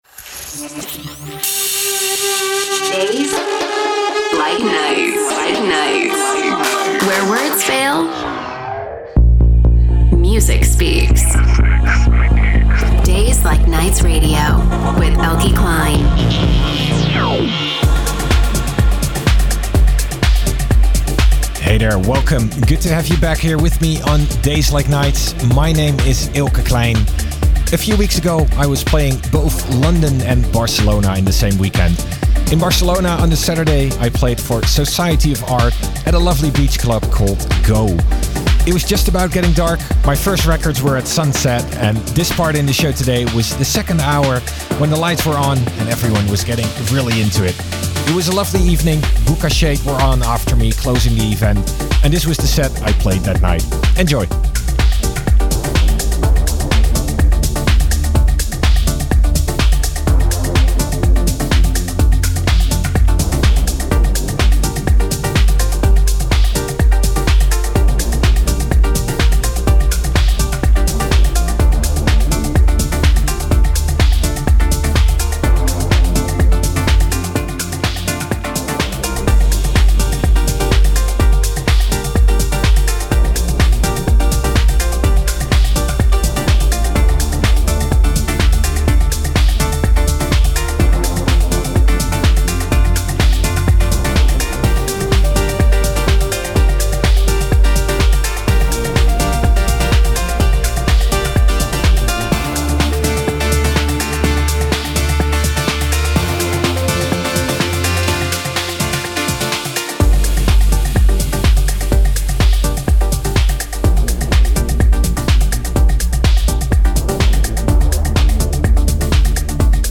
Live Set